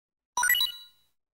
Play, download and share Itempickup original sound button!!!!
itempickup.mp3